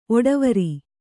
♪ oḍavari